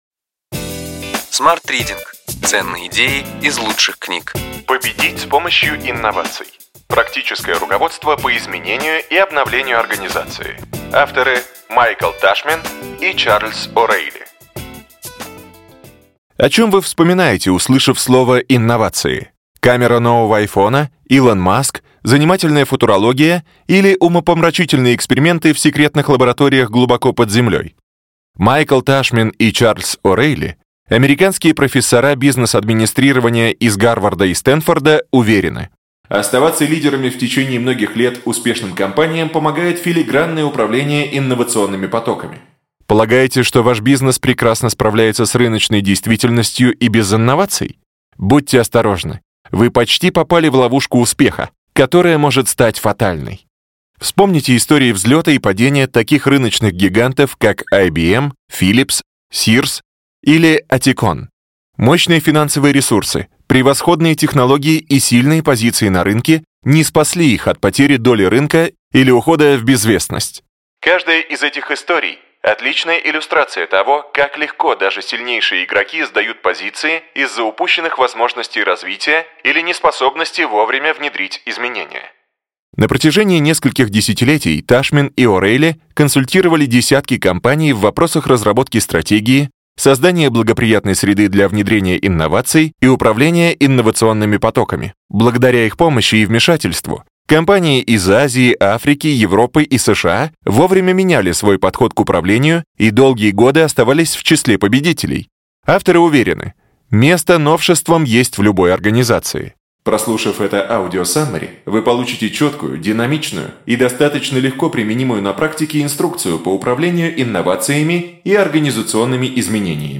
Аудиокнига Ключевые идеи книги: Победить с помощью инноваций.